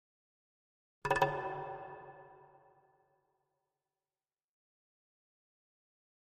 Jungle Drums Fear Fast Thin Hits Version 1